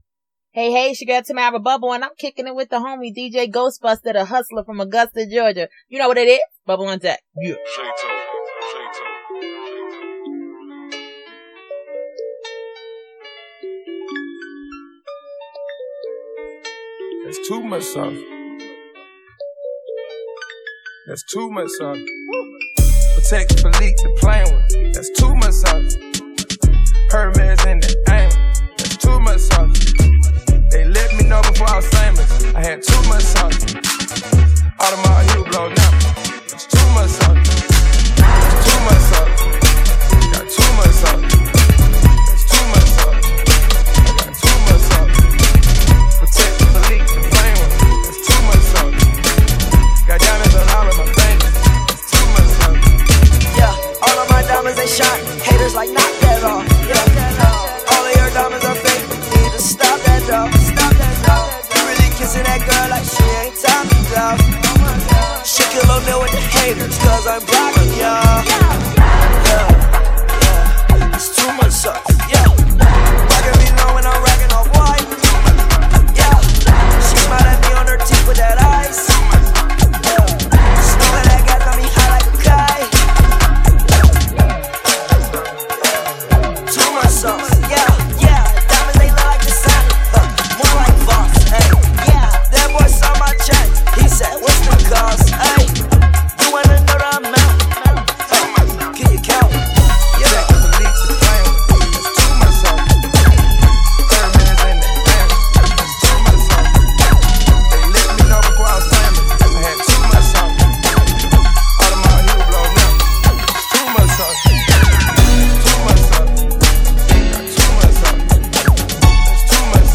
Hiphop
Blazing New School & Old School Hits